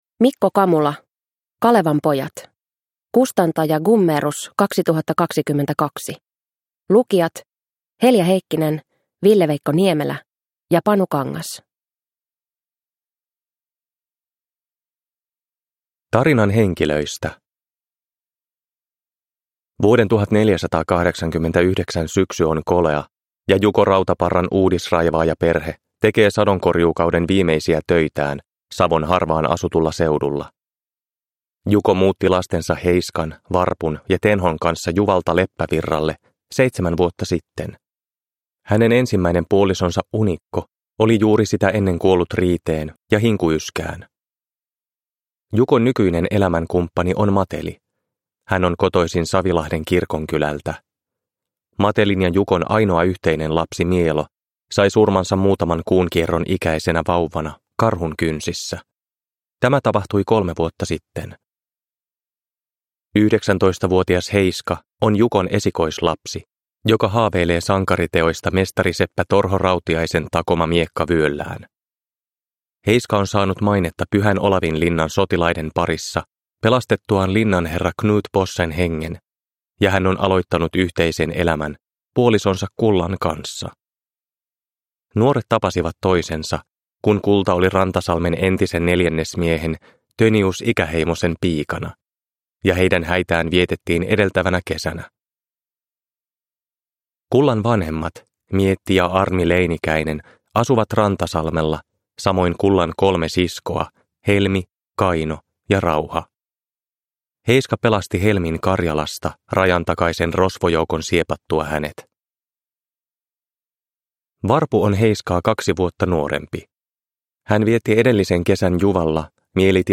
Kalevan pojat – Ljudbok – Laddas ner